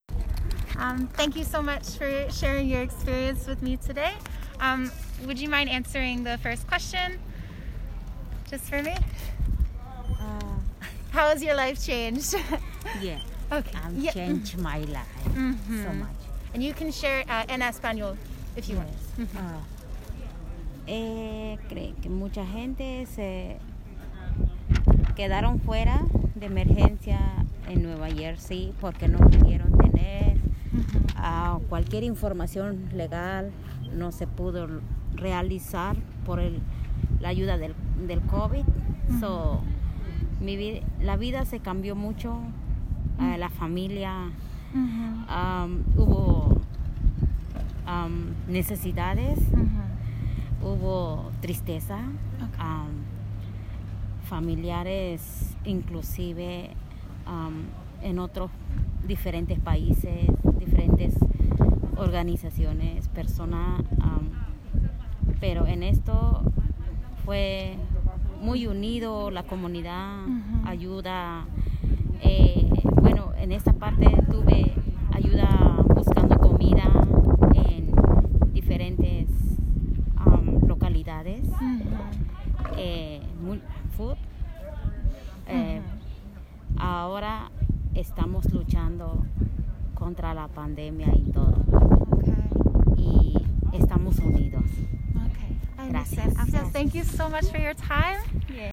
Participant 490 Community Conversations Interview